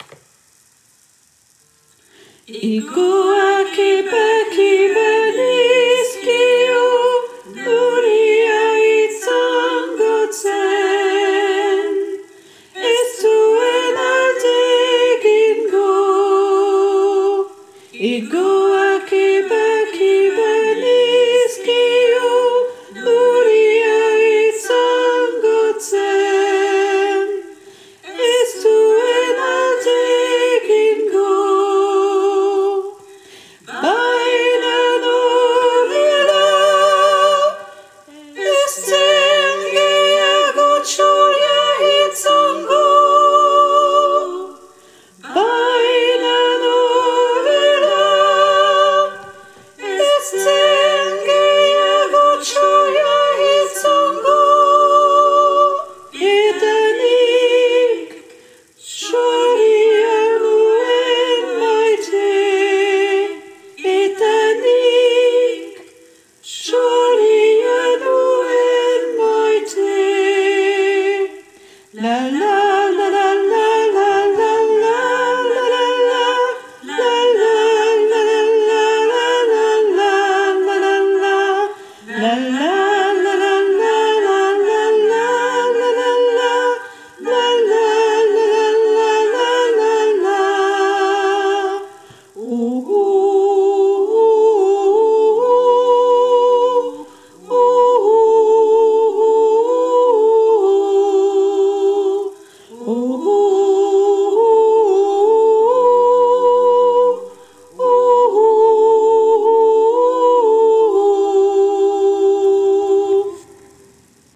- Chant pour choeur à 4 voix mixtes (SATB)
Tenor et autres voix en arrière-plan (version chantée)